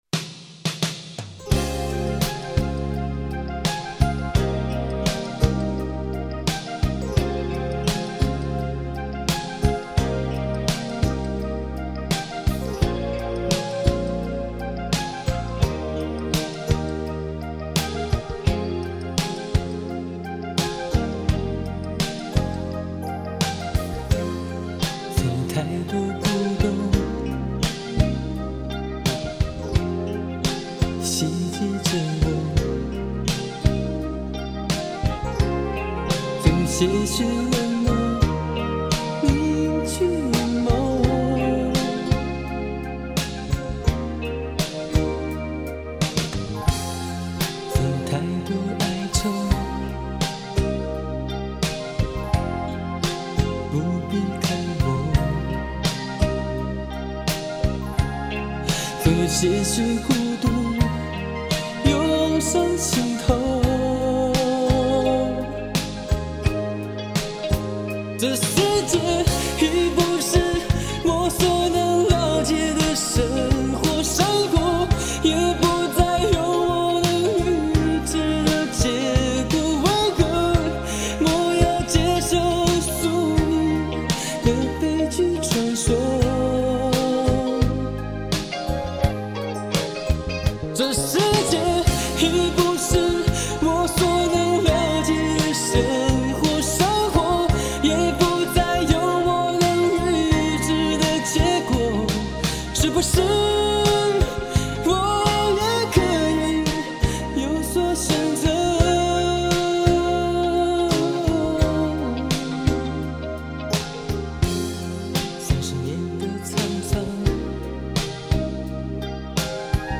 音质太差